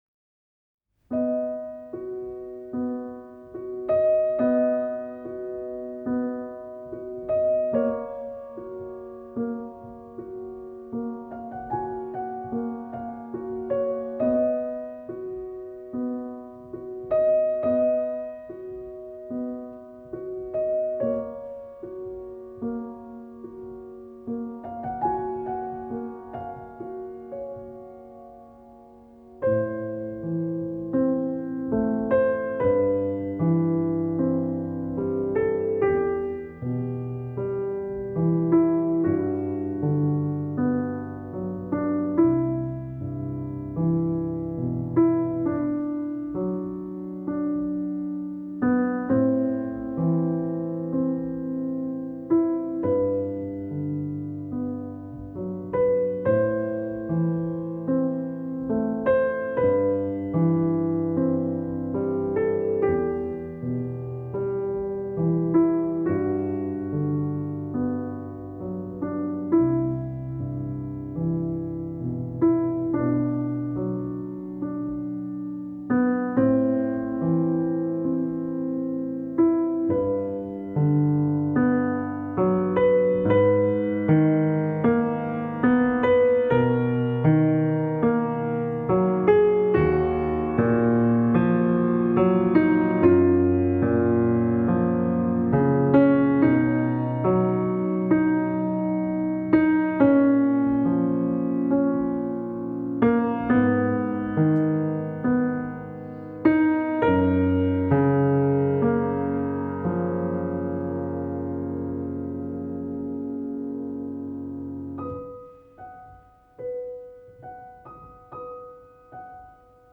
World Premiere Recording
piano